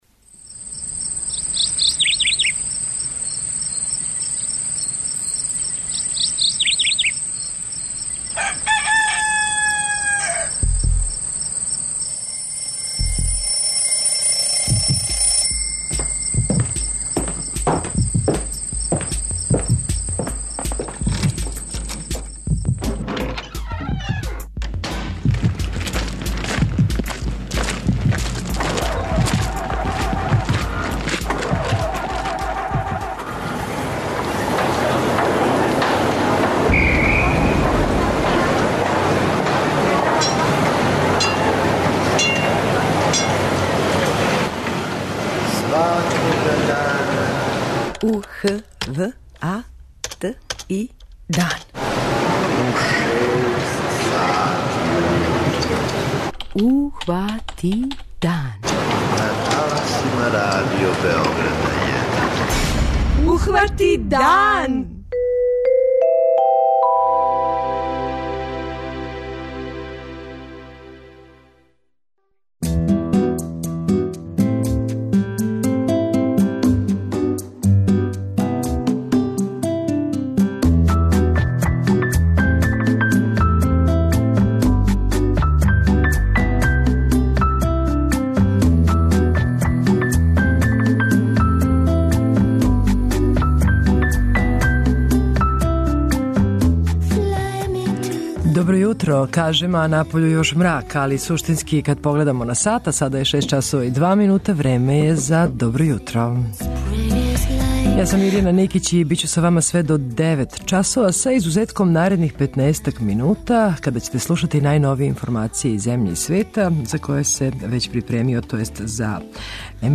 Из садржаја Јутарњег програма издвајамо: